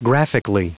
Prononciation du mot : graphically
graphically.wav